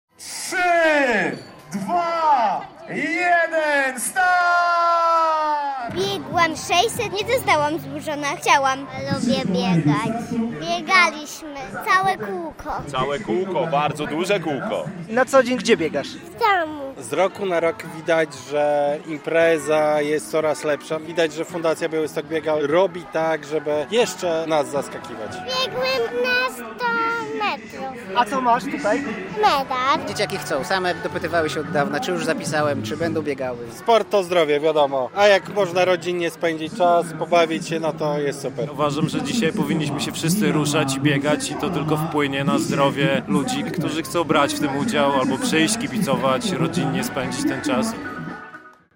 Biegi dla dzieci na 13. Białystok Biega - relacja